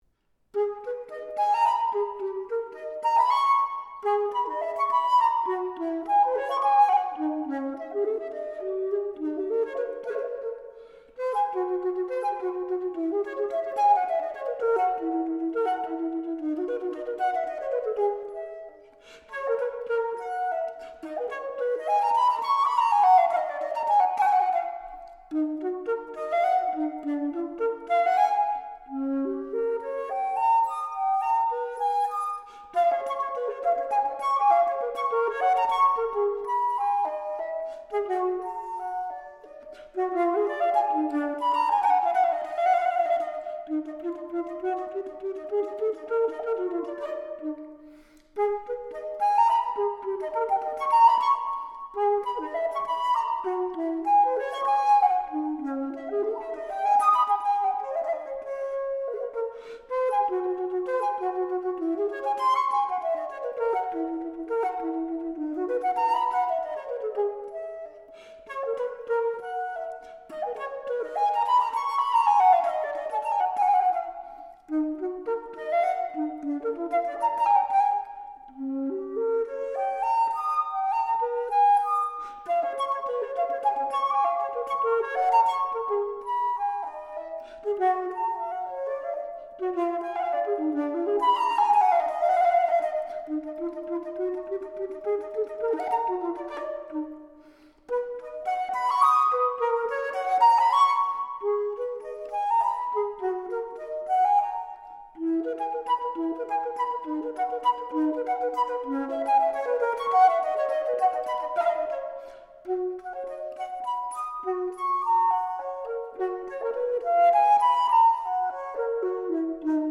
flûte traversière